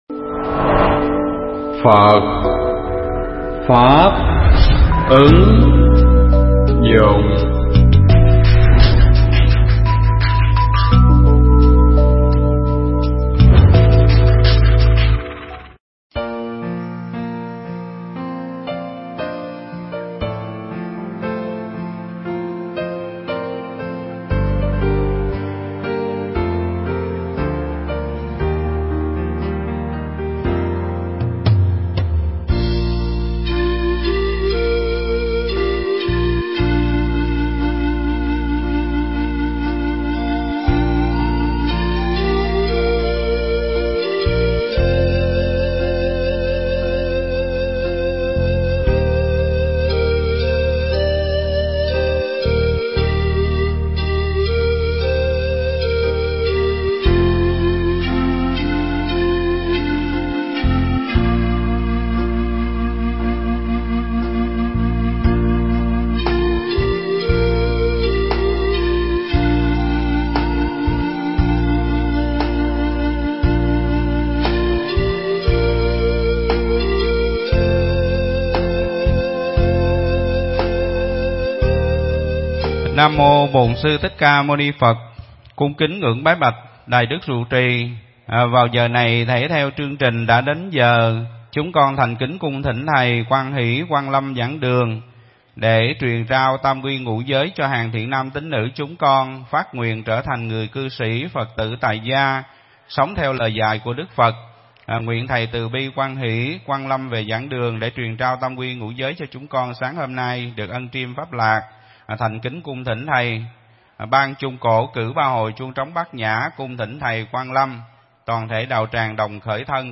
Pháp thoại Phật Pháp Vấn Đáp 20
trong đại lễ Phật Đản ở tu viện Tường Vân